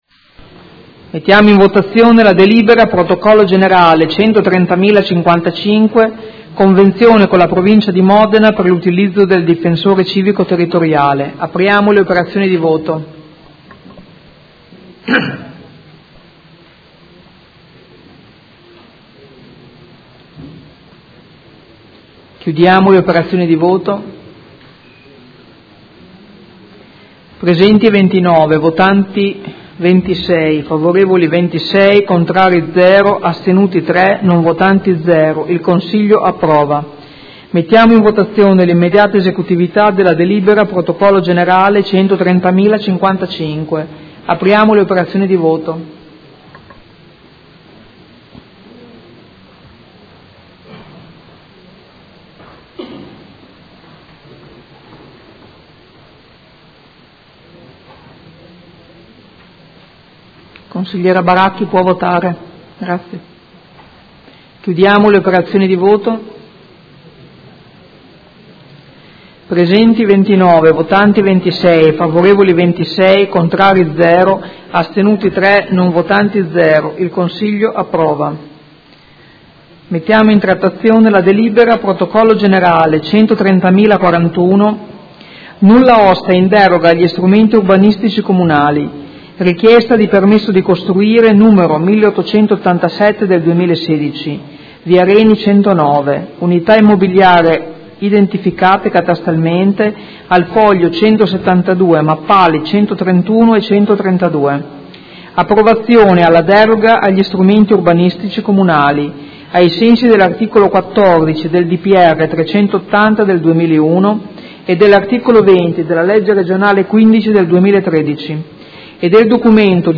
Seduta del 22/09/2016 Proposta di deliberazione: Convenzione con la Provincia di Modena per l’utilizzo del Difensore Civico Territoriale– Approvazione. Votazione delibera ed immediata esecutività